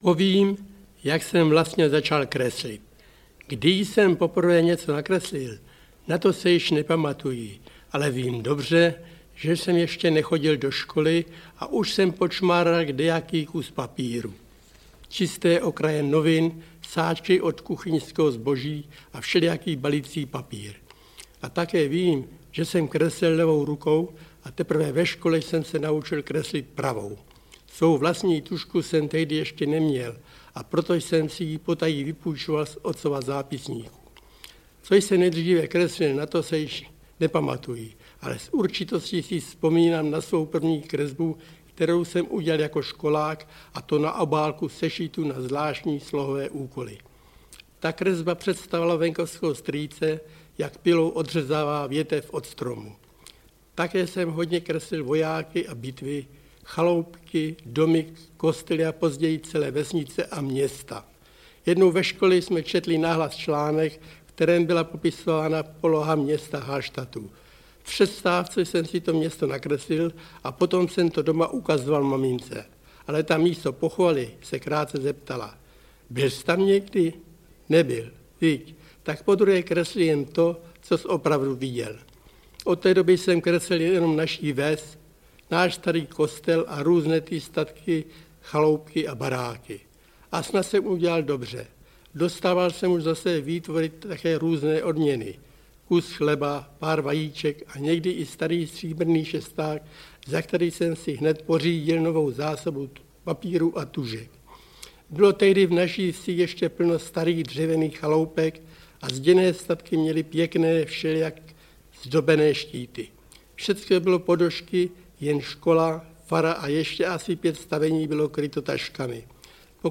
Vzpomínky národního umělce Josefa Lady / Vzpomínky na Jaroslava Haška - Josef Lada - Audiokniha
• Čte: Josef Lada